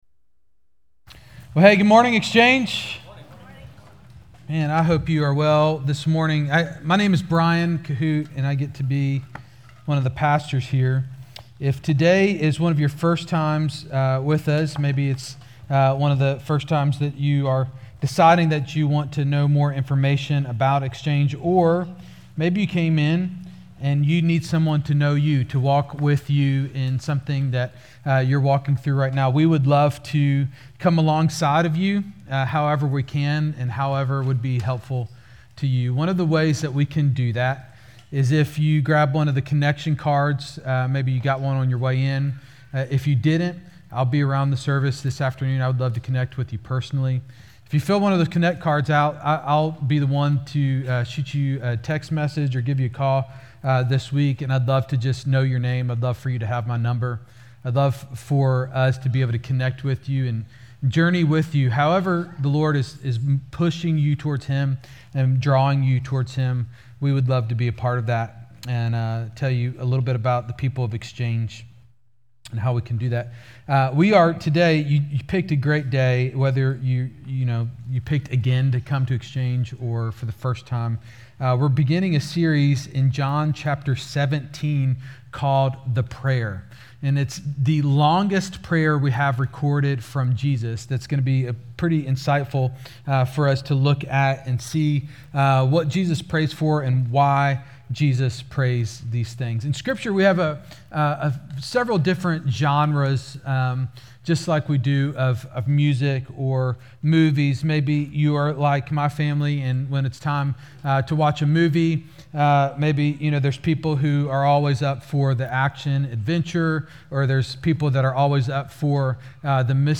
Sunday morning sermons from Exchange Church in Rolesville, NC.